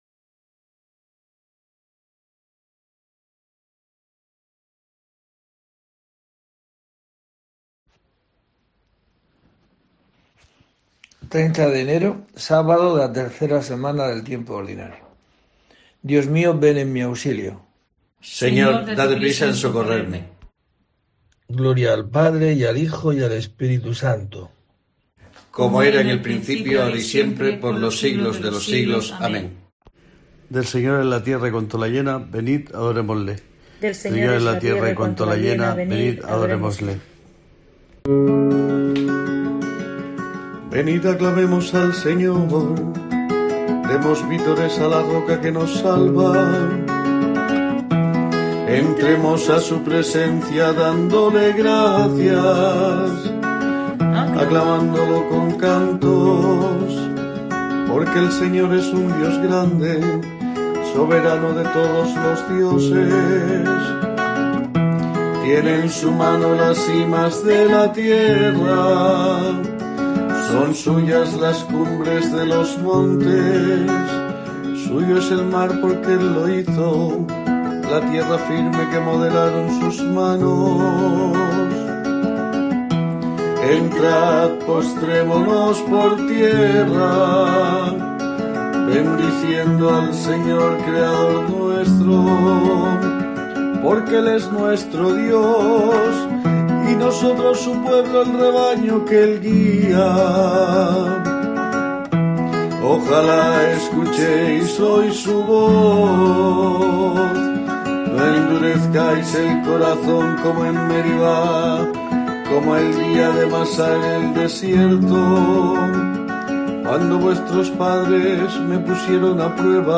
30 de enero: COPE te trae el rezo diario de los Laudes para acompañarte